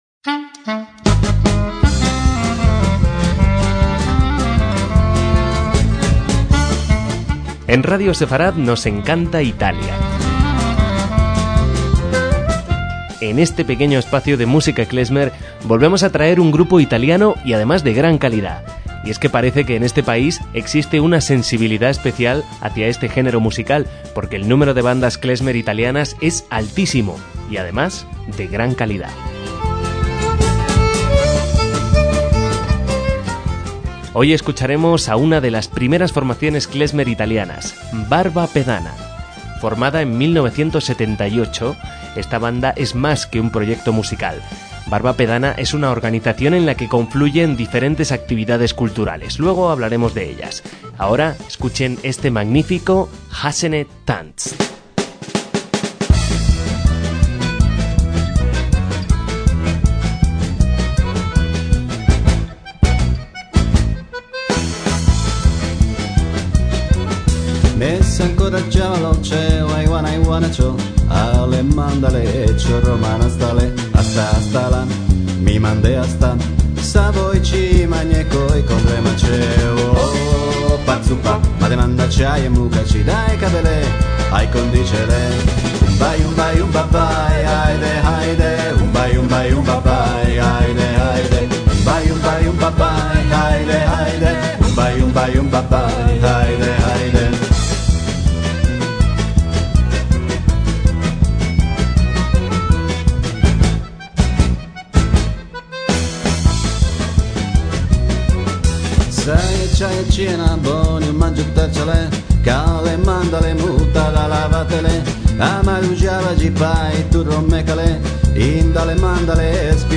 MÚSICA KLEZMER
música klezmer y gitana del Este de Europa